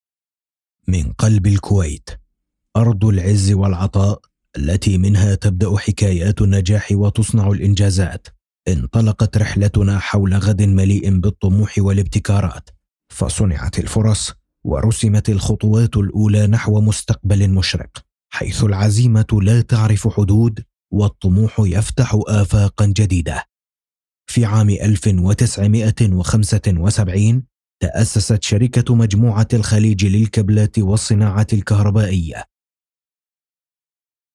Male
A warm, confident Arabic voice with a clear, polished tone that instantly builds trust. The delivery is natural, steady, and engaging, balancing professionalism with approachability.
Documentary